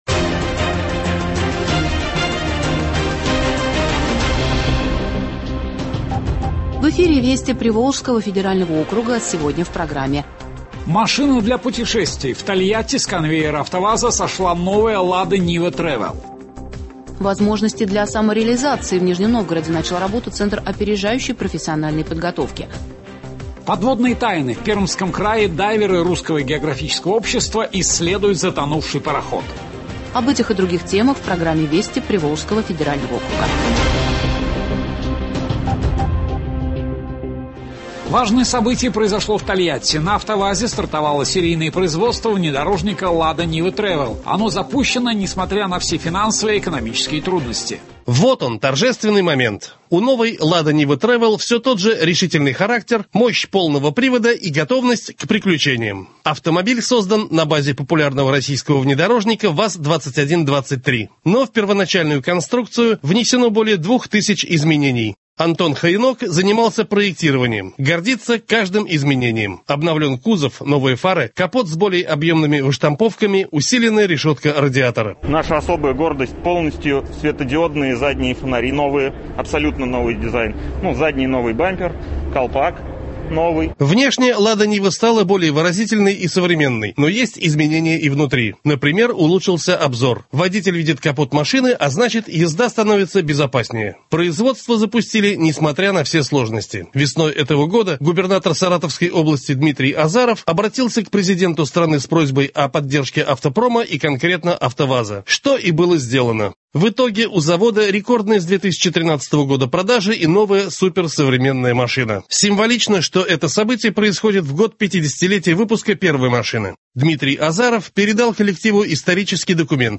Радиообзор событий в регионах ПФО.